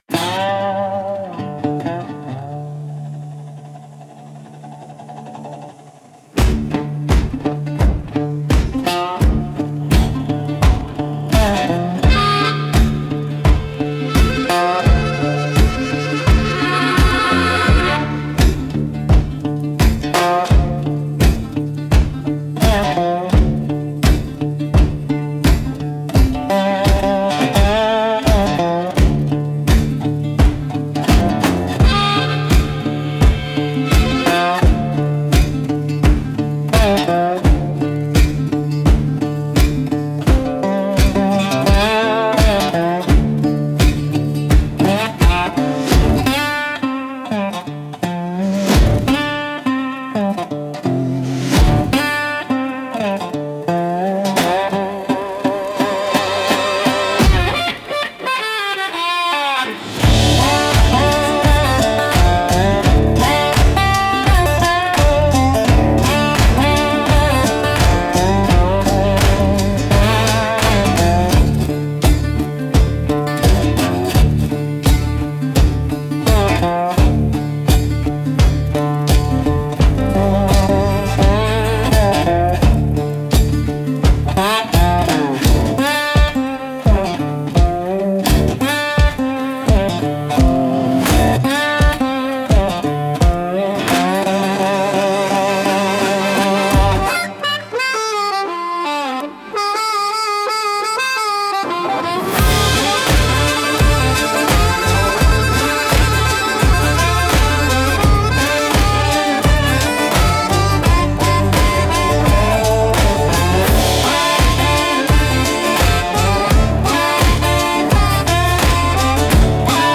Instrumental - Ember Pads 2.43